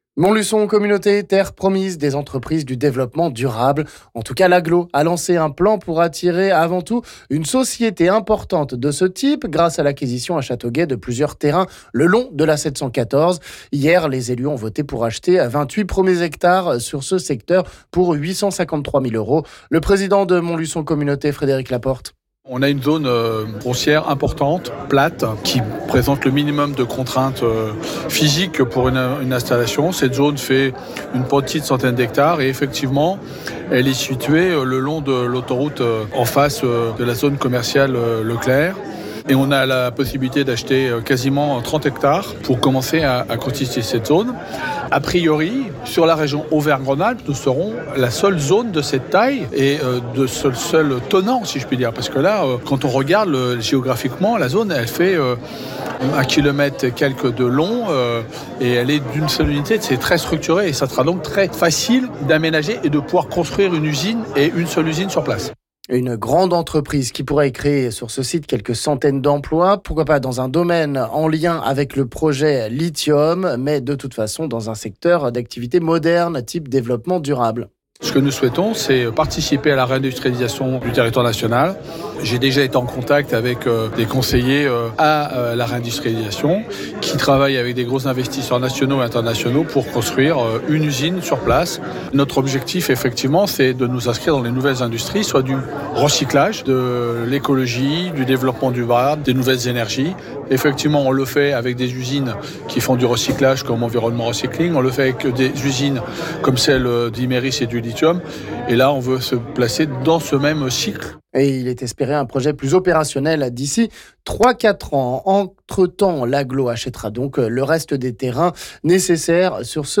On écoute ici le président de l'agglo Frédéric Laporte...